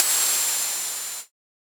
Index of /musicradar/ultimate-hihat-samples/Hits/ElectroHat C
UHH_ElectroHatC_Hit-05.wav